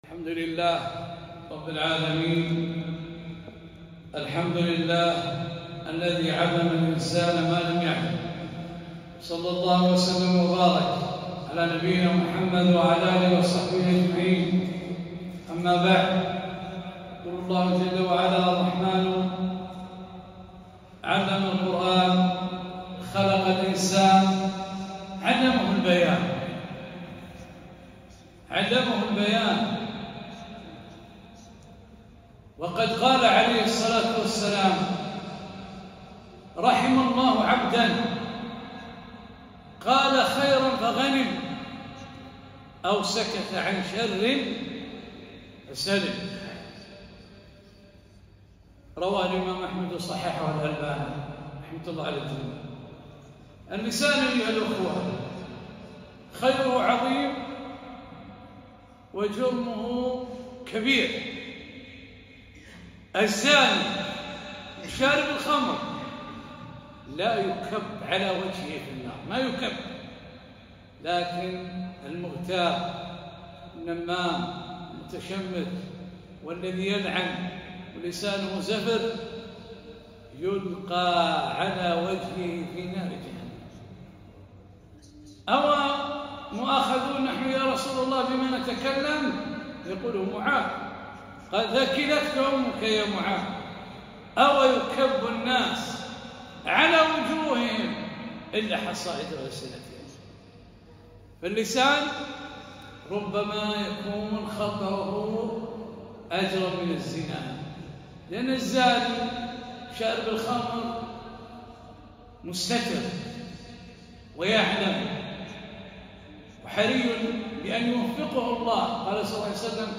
كلمة - أمسك عليك لسانك